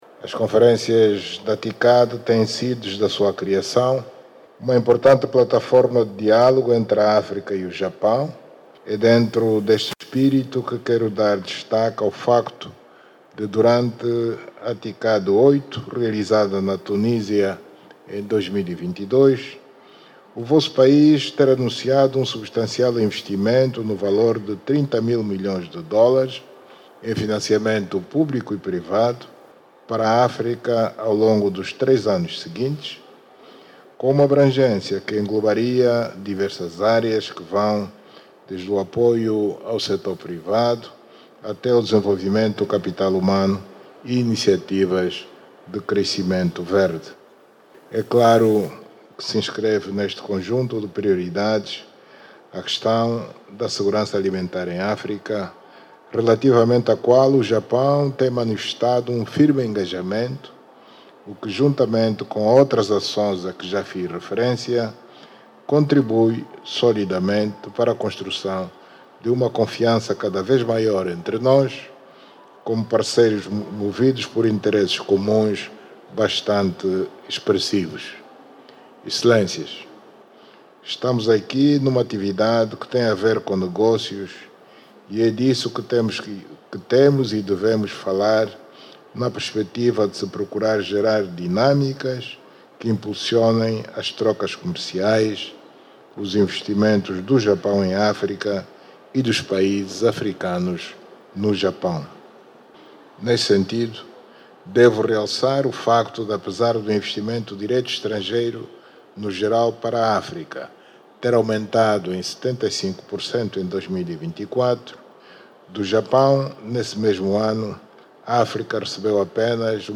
O líder da União Africana, João Lourenço, que está em Tóquio para participar na nona conferência internacional de Tóquio sobre o desenvolvimento de África, discursou na abertura do Fórum de Negócios Japão/África, evento em que participaram mais de cinquenta empresas.